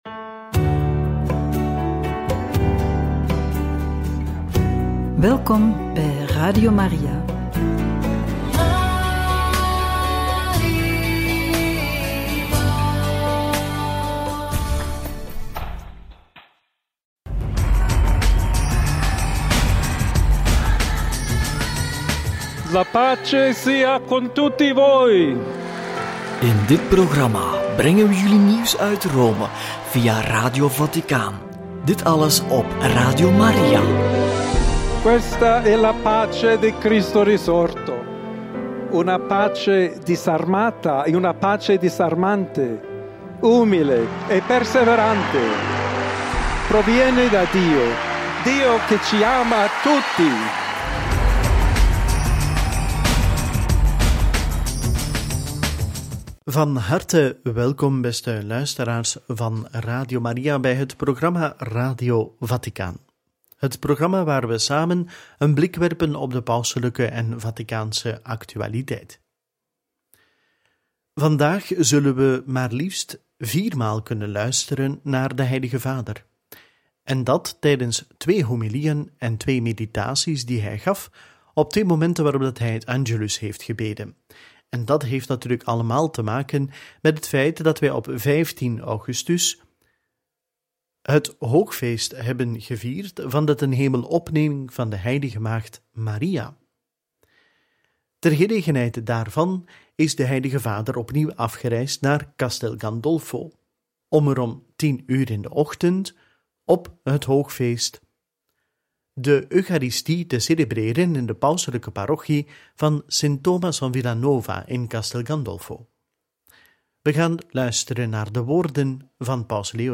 Homilie hoogfeest Maria Tenhemelopneming – Angelus 15/8 – Homilie 20e zondag – Angelus 17/8 – Radio Maria